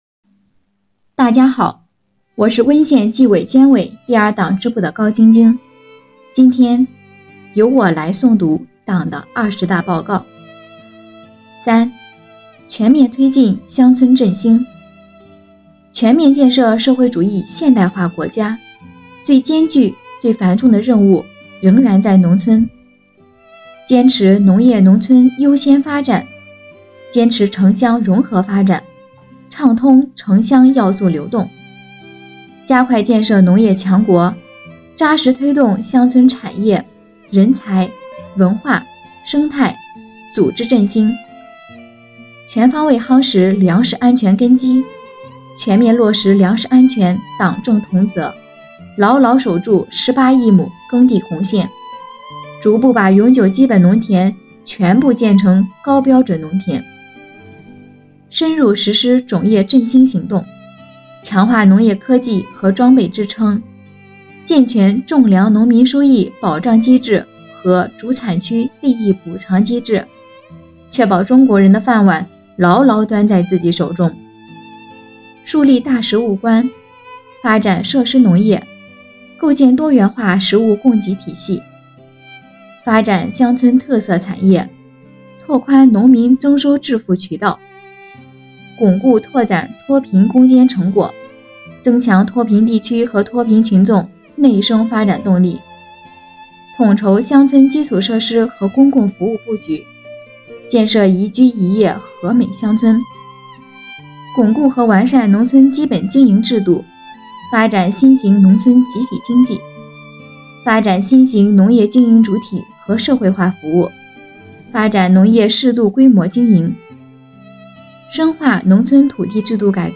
诵读内容